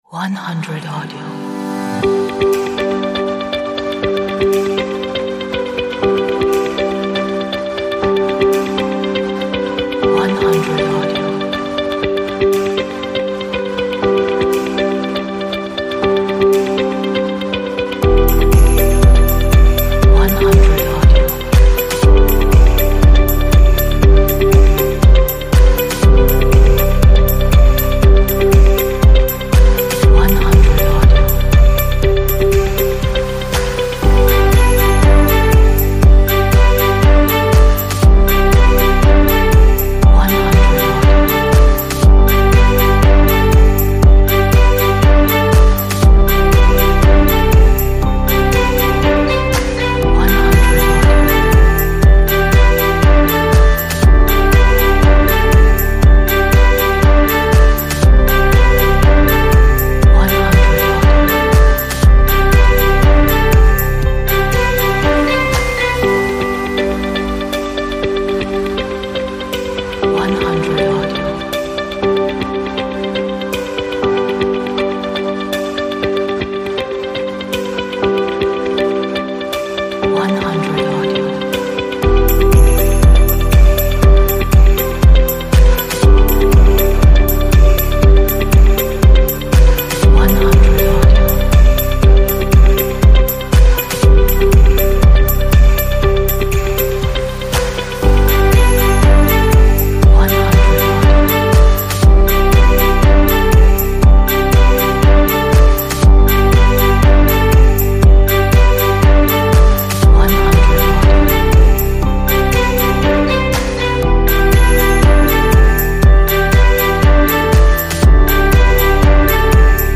a nice pop upbeat inspirational track
这是一首很好的 乐观的 鼓舞人心的流行音乐。